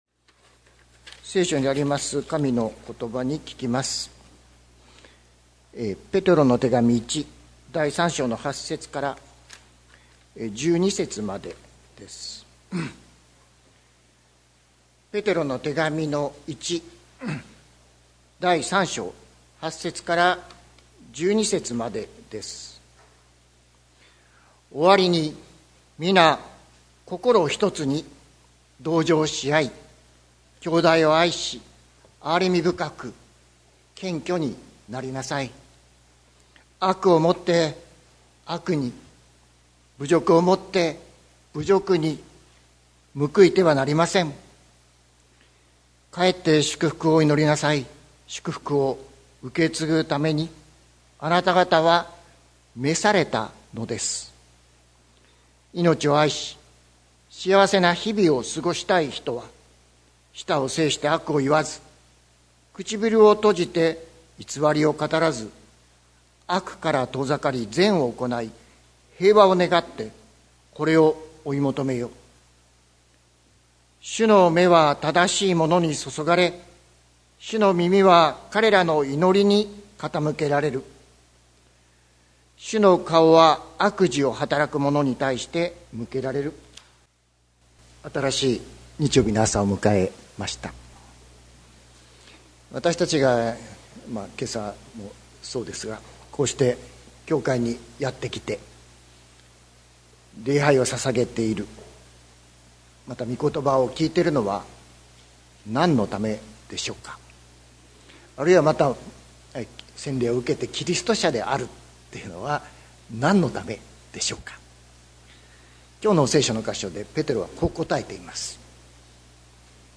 2024年11月24日朝の礼拝「祝福を受け継ぐために」関キリスト教会
説教アーカイブ。